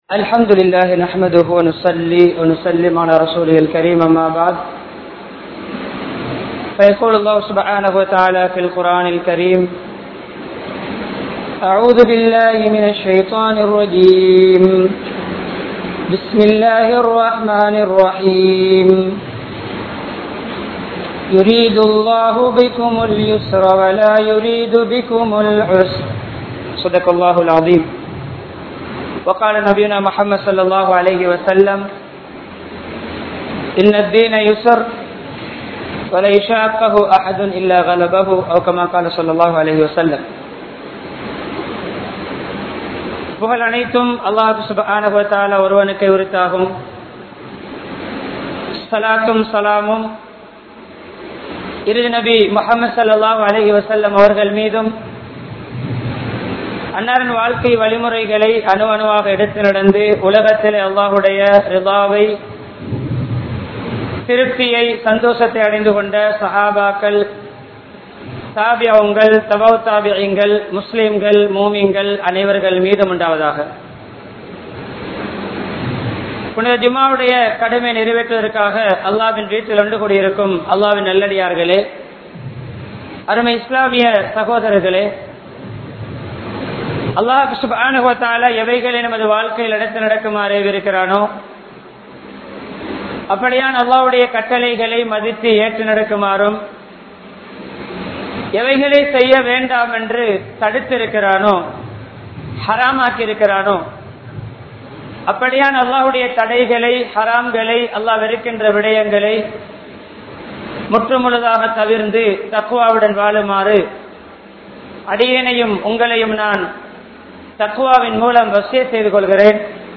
Islam Ilahuvaana Maarkam (இஸ்லாம் இலகுவான மார்க்கம்) | Audio Bayans | All Ceylon Muslim Youth Community | Addalaichenai